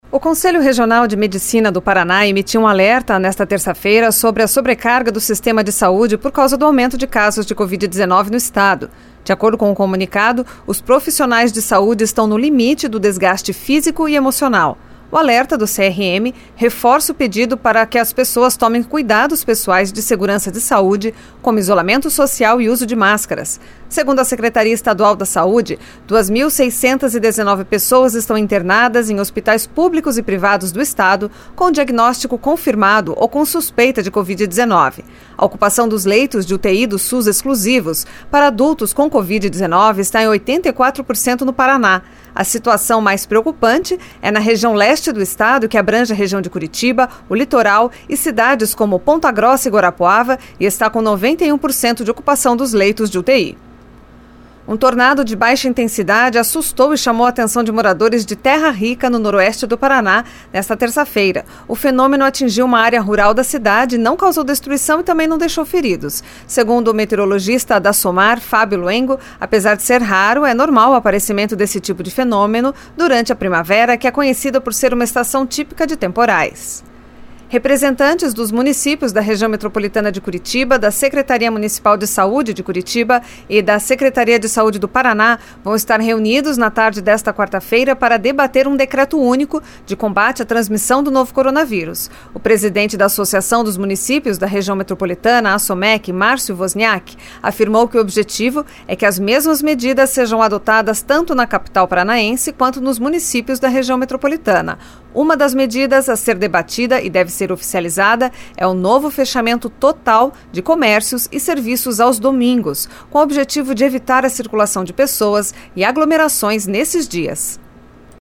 Giro de Notícias Manhã SEM TRILHA.